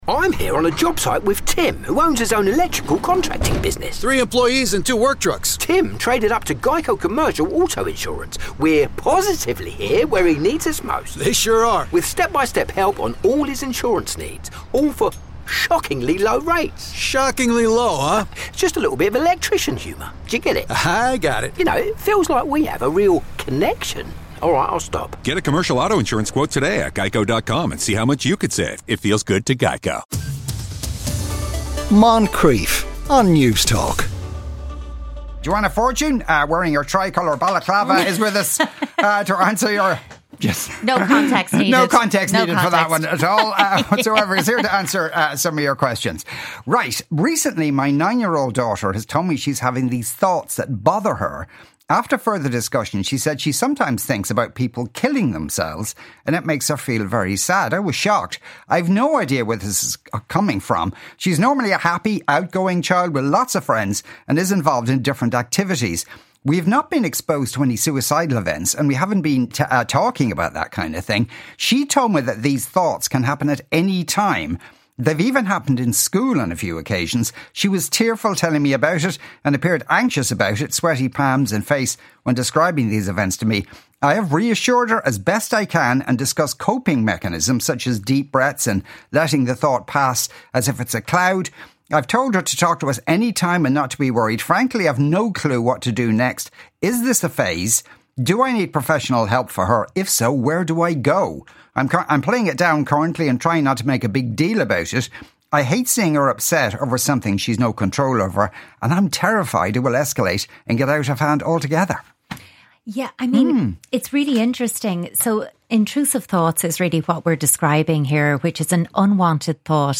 Every week Moncrieff is joined by a parenting expert and child psychologist each week to answer questions sent in from listeners.
CONVERSATION THAT COUNTS | Ireland’s national independent talk station for news, sport, analysis and entertainment